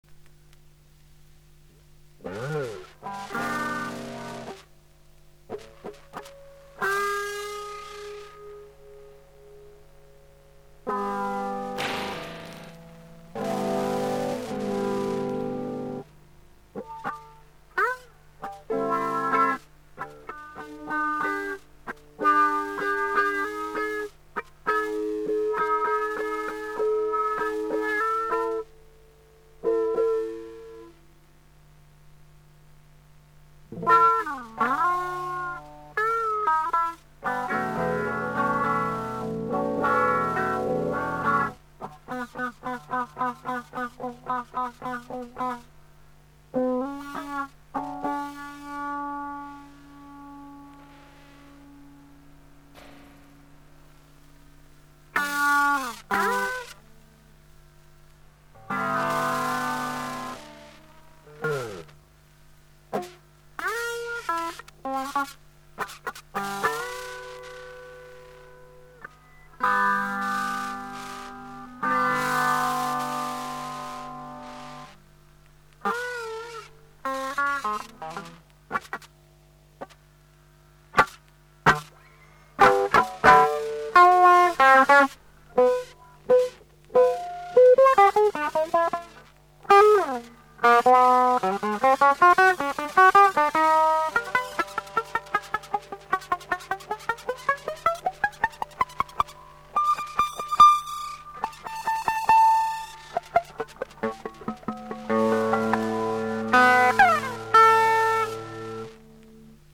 テープさん御機嫌麗しゅぅも廻ってる内の角馴染みスムーズと周りし